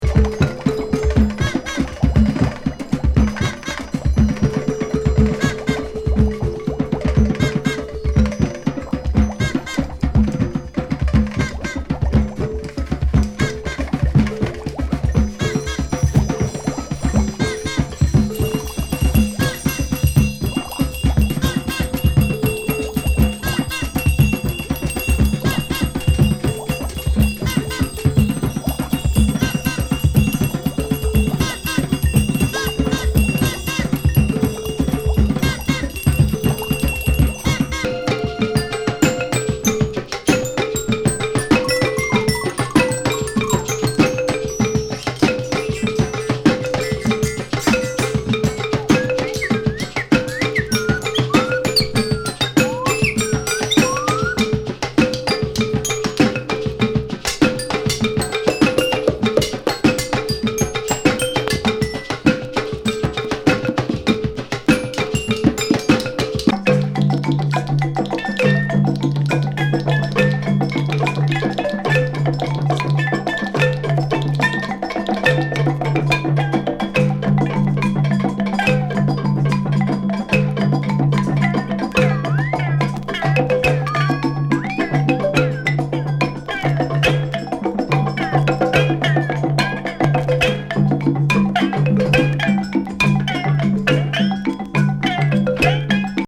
フレンチ・カリビアン・パーカッション奏者の空想世界旅行的リズム・アルバム。ブクブク水中遊戯